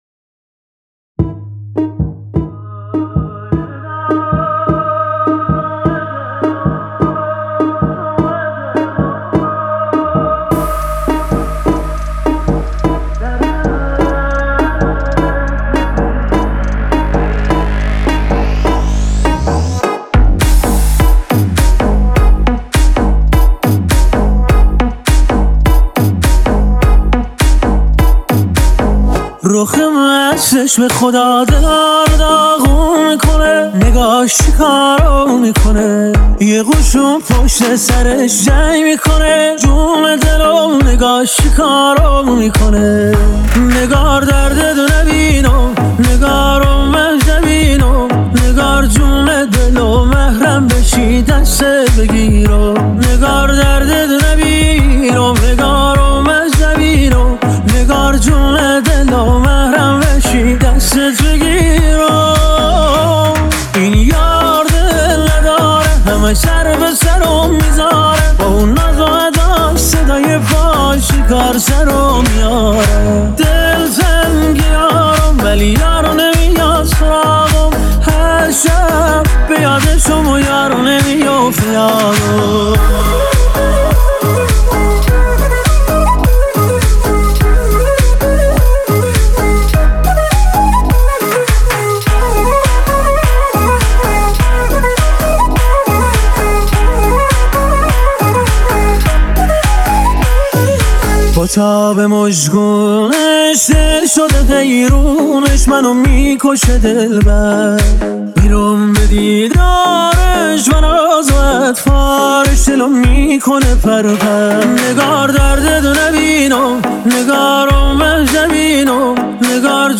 دانلود آهنگ شاد